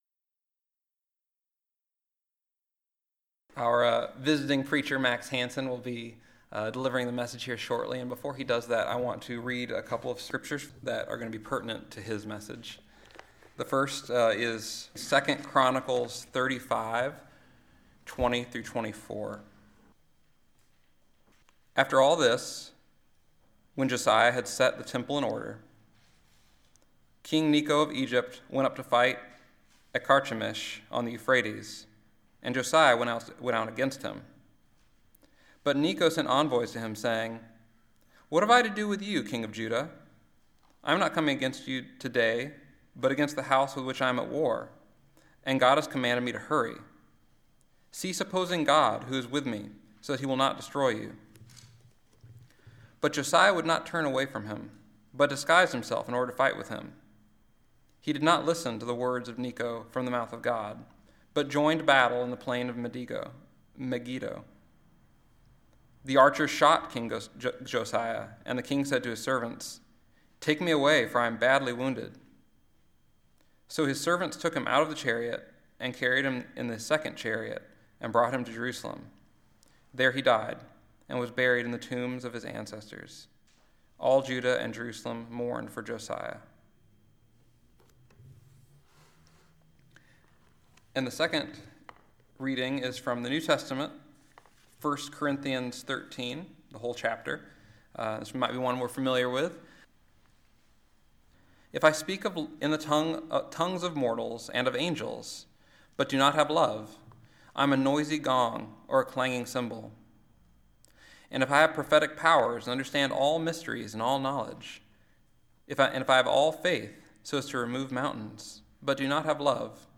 Listen to the most recent message from Sunday worship at Berkeley Friends Church, “Perfection Is a Flaw.”